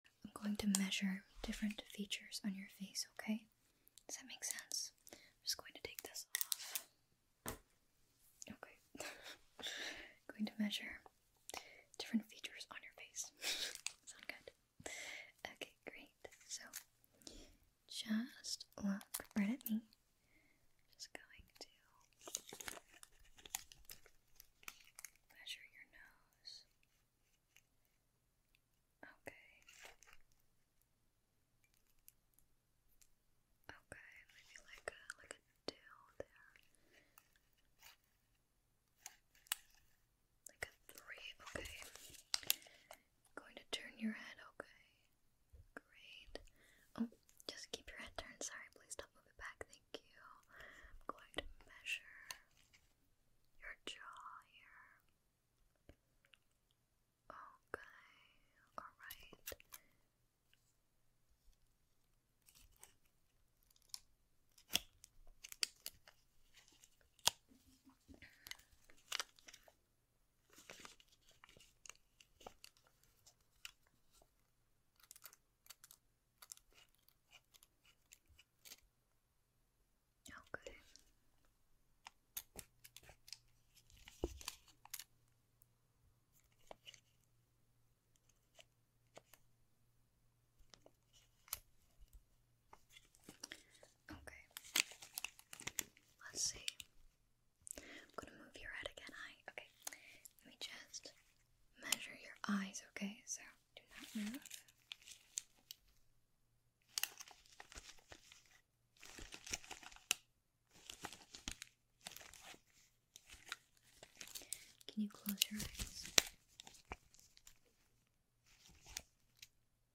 Measuring your facial features ASMR